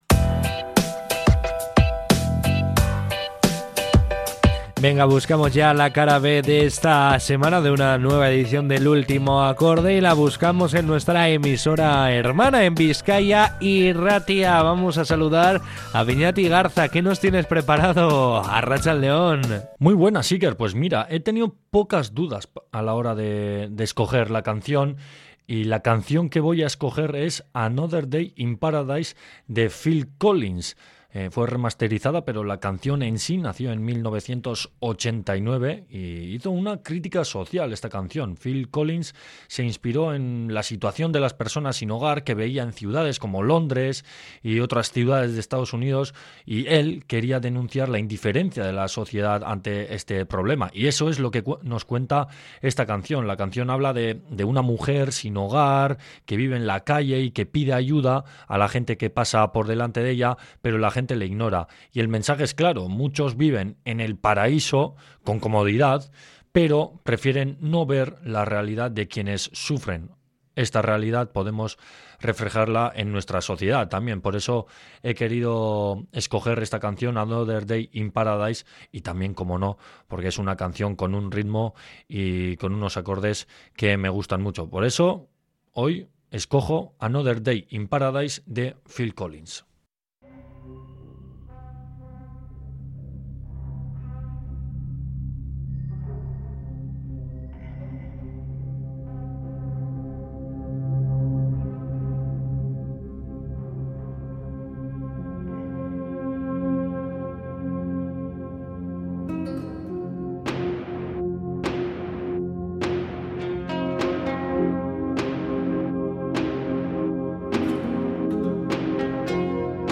En esta nueva entrega de La Cara B dentro de El Último Acorde, nos asomamos de nuevo a la redacción de nuestra emisora hermana, Bizkaia Irratia, para conocer las preferencias musicales de uno de sus compañeros.
Aunque la versión que hemos podido escuchar es un remaster, el tema original vio la luz en el año 1989.